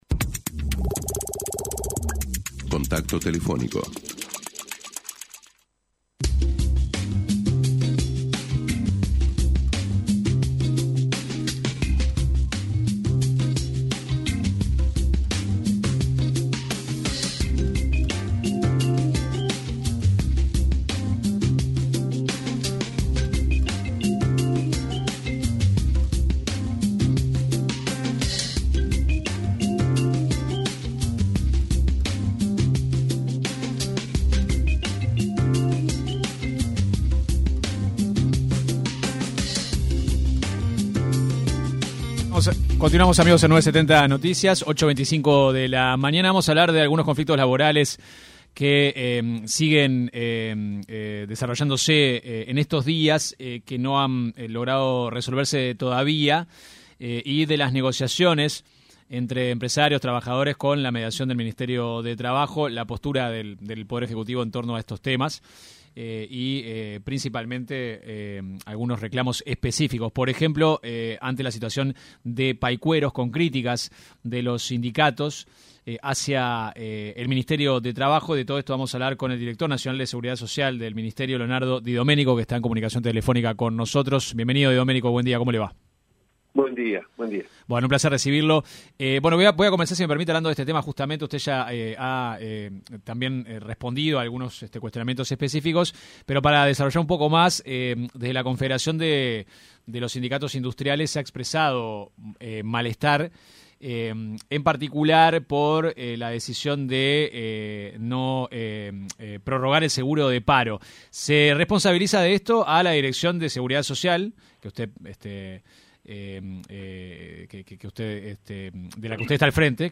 El director nacional de Seguridad Social del Ministerio de Trabajo, Leonardo Di Doménico, se refirió en una entrevista con 970 Noticias, a al situación que atraviesan 89 trabajadores de la curtiembre Paycueros que solicitaron a la cartera un seguro de paro especial, porque la empresa continúa cerrada.